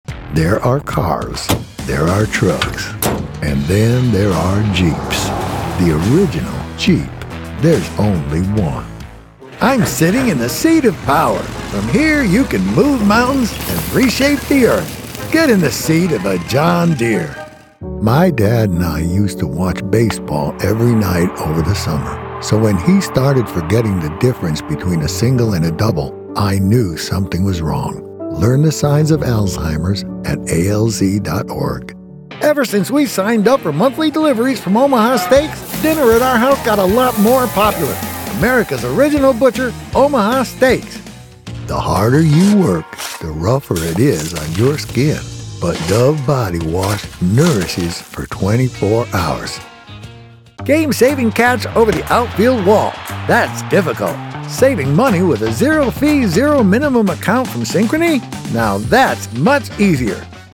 Warm. Comforting. Authentic.
Commercial Demo
Engaging and persuasive voiceovers for television, radio, and online advertisements.